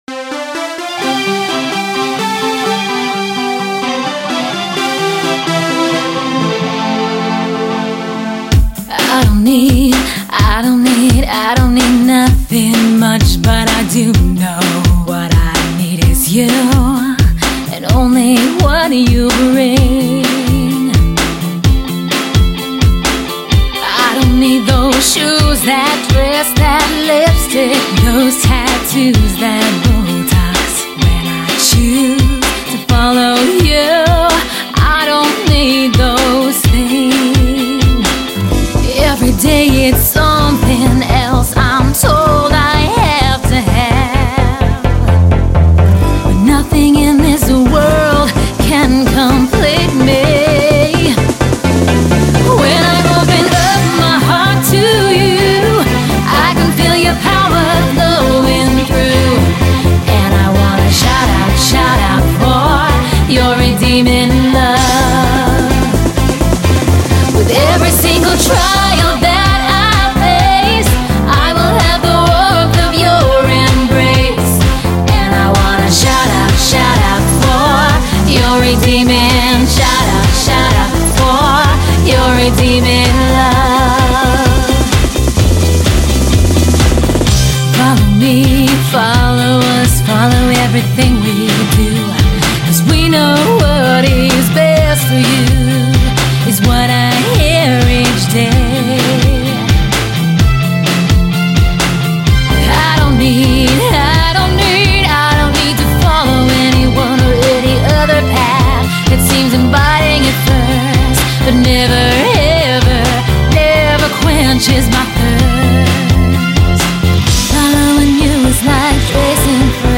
Genre Religious